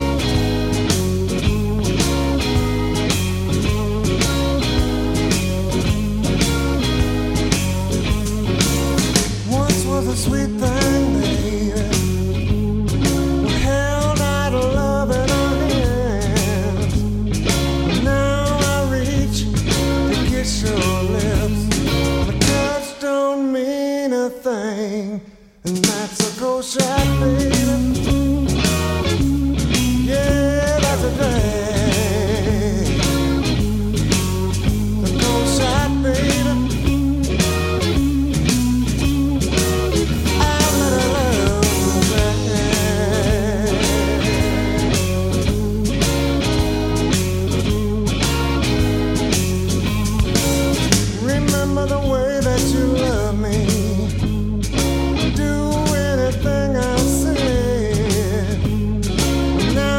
Рок
виртуоз блюзовой гитары